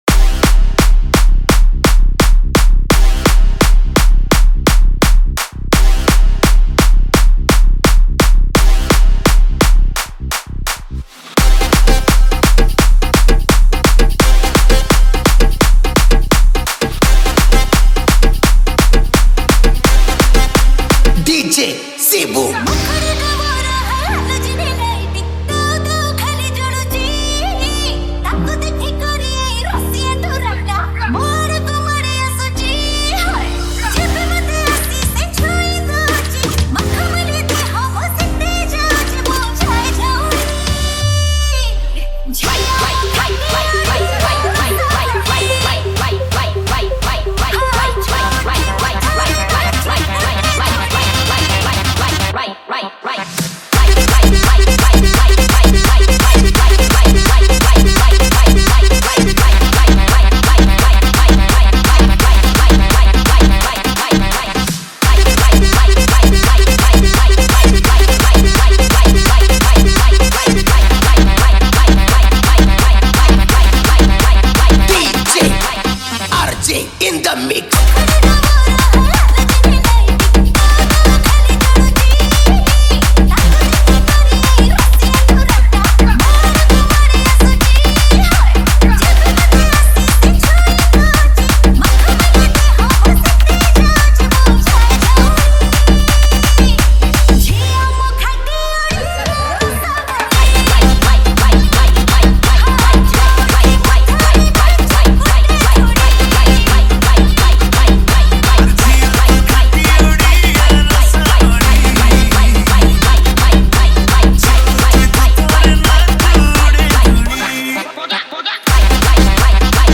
New Odia Dj Song 2025